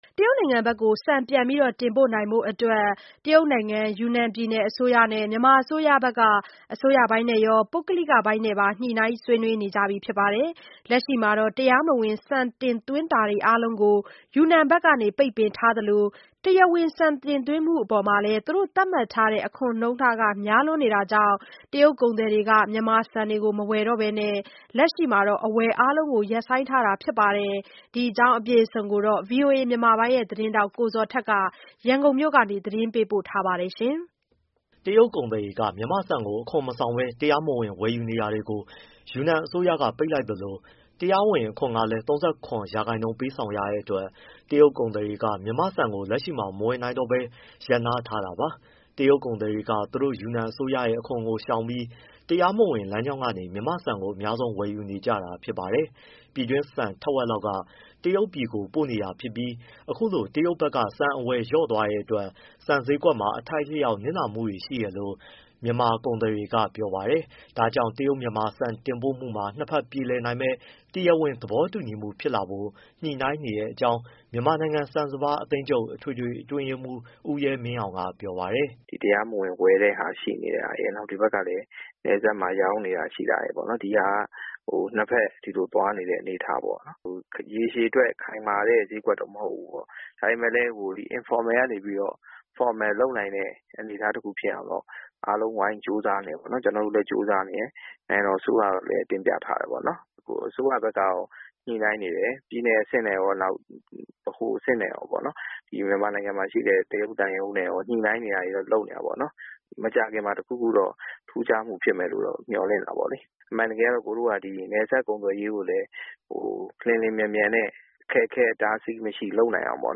ရန်ကုန်ကနေ သတင်းပေးပို့ထားပါတယ်။